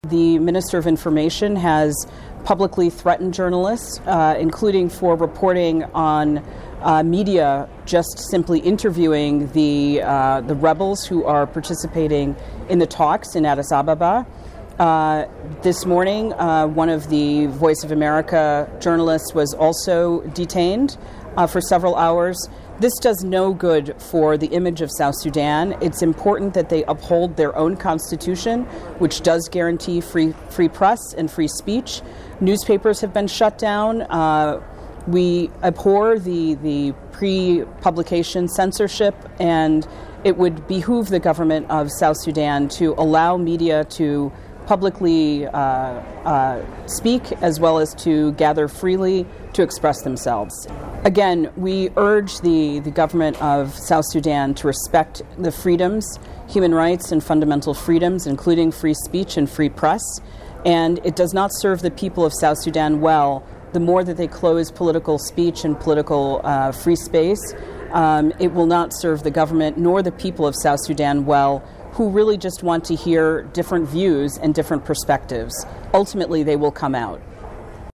"This is not a conflict that should or can be won through the use of force and weapons," Page told VOA in an interview conducted Thursday at the State Department in Washington.